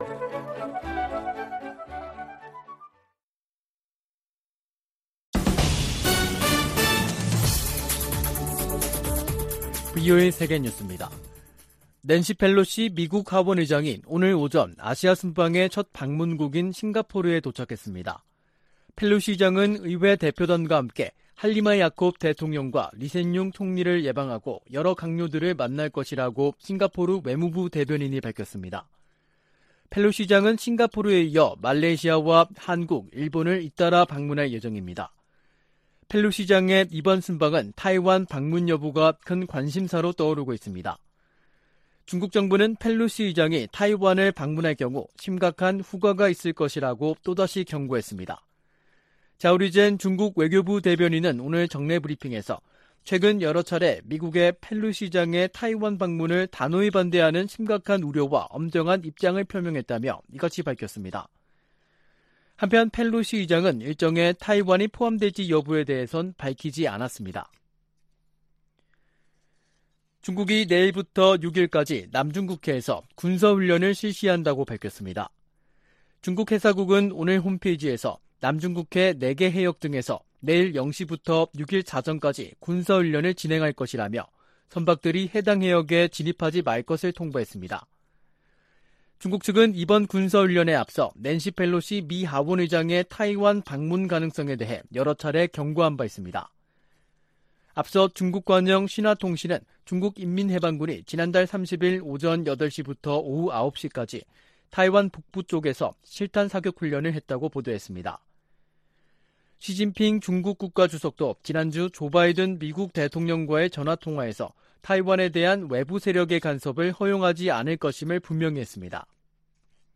VOA 한국어 간판 뉴스 프로그램 '뉴스 투데이', 2022년 8월 1일 2부 방송입니다. 백악관 국가안보회의(NSC) 고위관리가 미-한 연합훈련과 관련해 준비태세의 중요성을 강조하고, 한반도 상황에 맞게 훈련을 조정하고 있다고 밝혔습니다. 미 국방부는 중국의 사드 3불 유지 요구와 관련해 한국에 대한 사드 배치는 두 나라의 합의에 따라 결정될 것이라는 입장을 밝혔습니다. 밥 메넨데즈 미 상원 외교위원장이 '쿼드'에 한국을 포함해야 한다고 말했습니다.